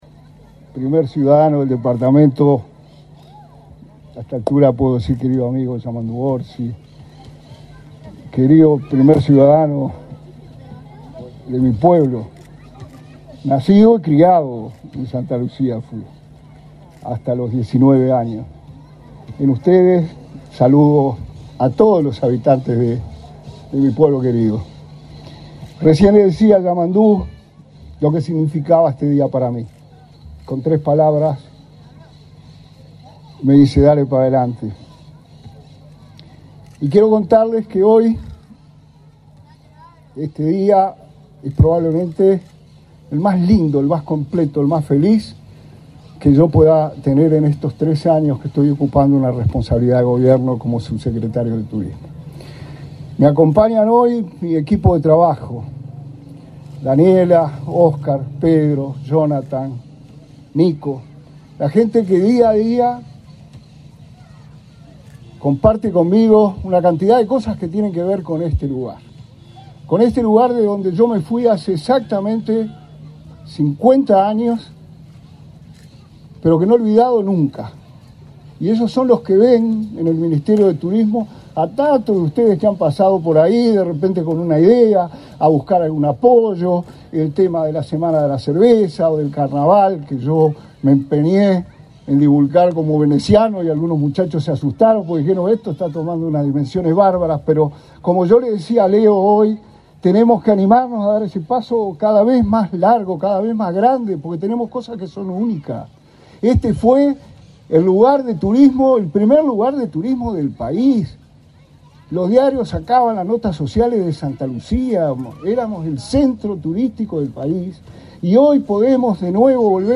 Palabras del subsecretario de Turismo, Remo Monzeglio
Palabras del subsecretario de Turismo, Remo Monzeglio 08/05/2023 Compartir Facebook X Copiar enlace WhatsApp LinkedIn El Ministerio de Turismo inauguró, este 8 de mayo, obras en el parque Clemente Estable de Santa Lucía. En el acto participó el subsecretario de Turismo, Remo Monzeglio.